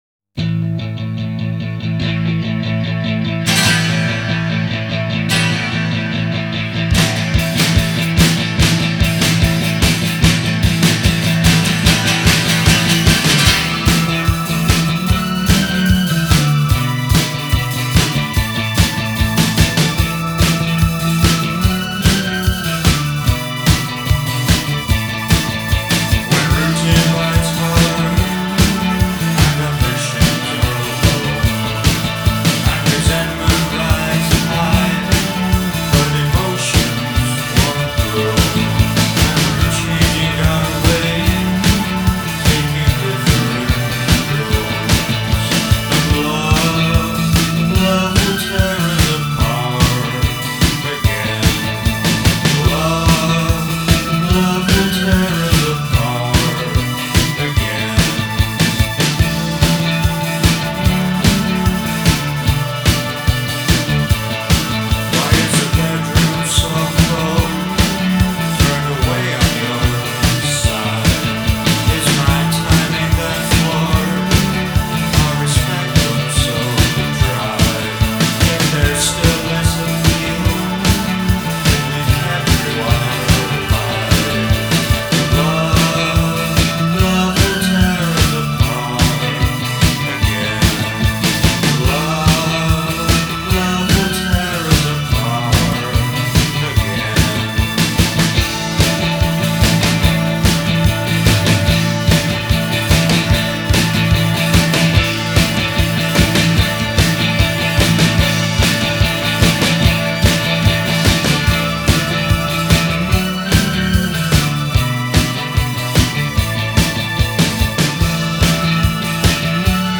Рок